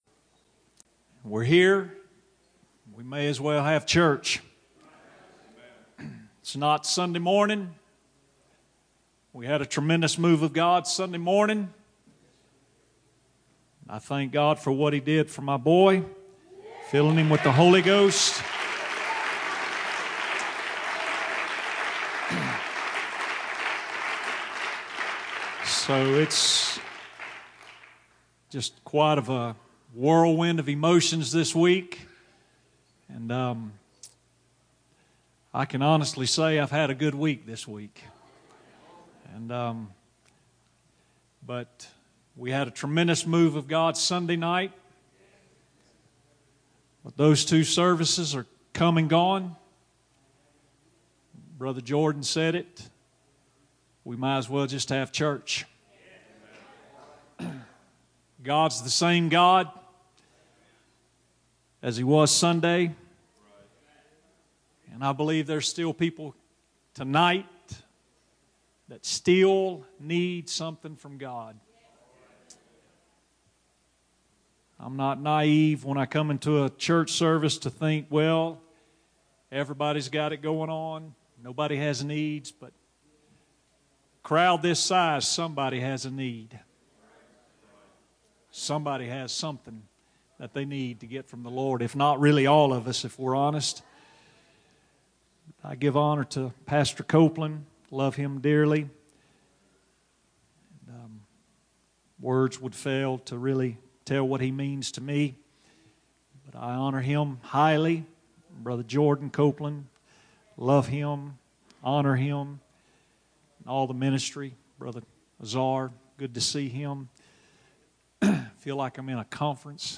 First Pentecostal Church Preaching 2019